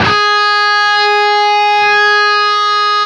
LEAD G#3 CUT.wav